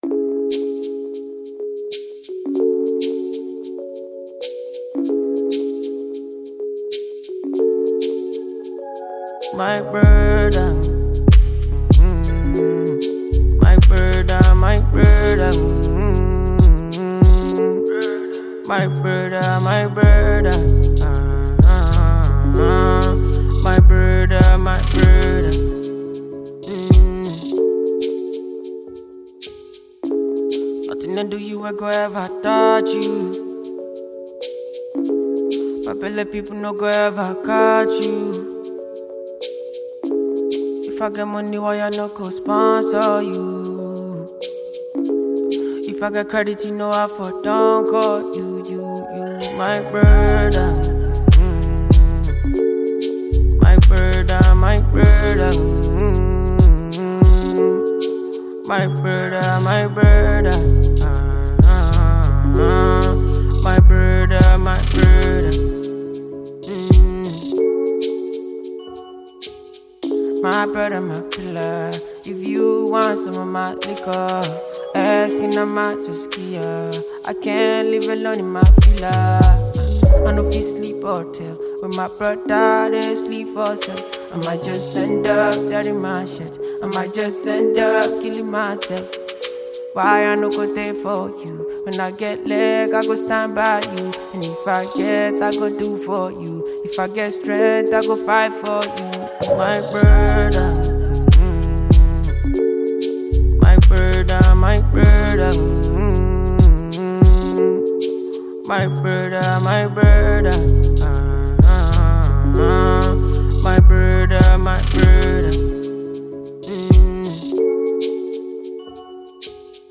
Another inspirational ballad
• Genre: Afrobeats